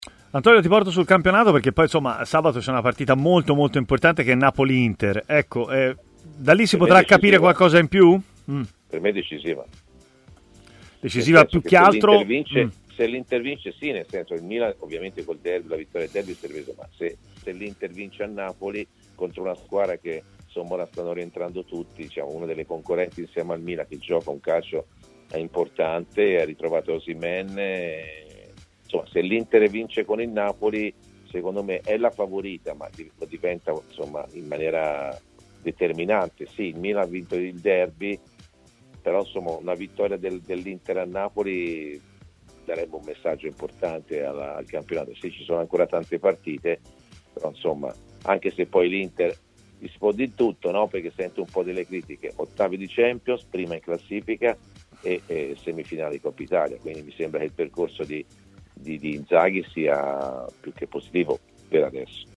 L'ex centrocampista Antonio Di Gennaro ha così parlato a Stadio Aperto, trasmissione di TMW Radio, della partita tra Napoli e Inter di sabato: "Sarà decisiva.